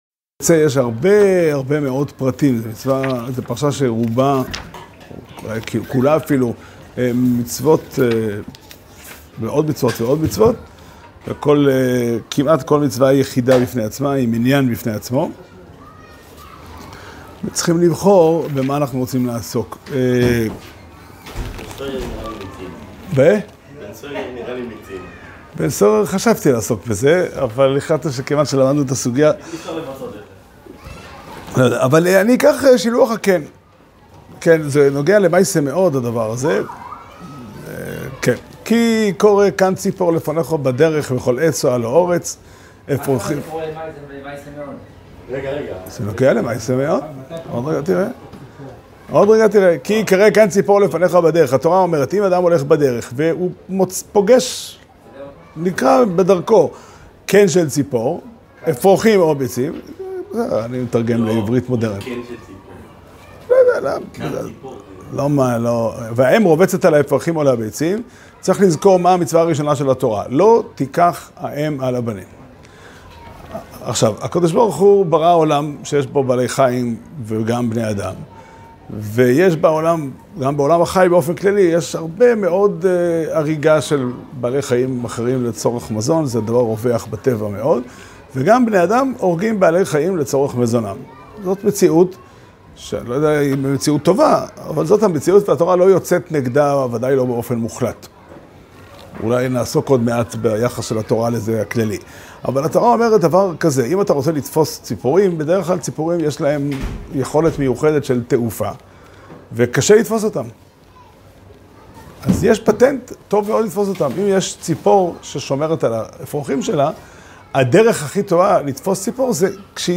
שיעור שנמסר בבית המדרש פתחי עולם בתאריך ו' אלול תשפ"ד